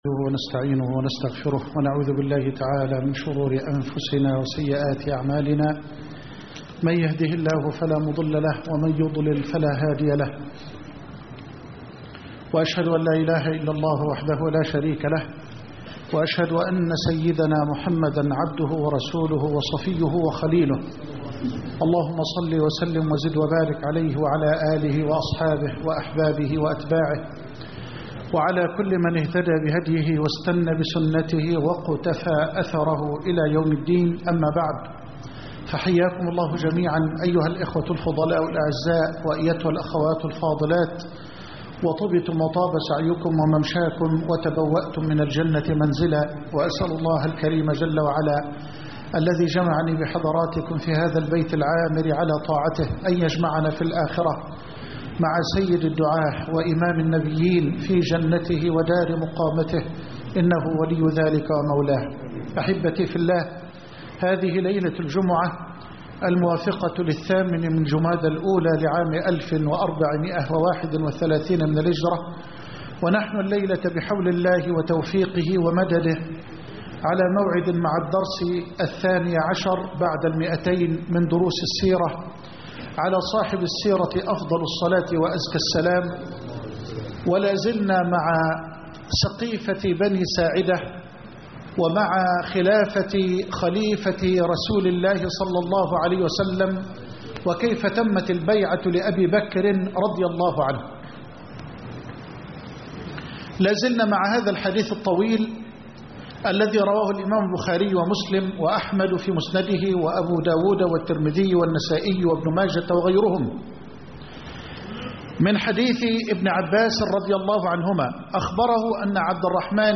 الدرس (212) الصديق ...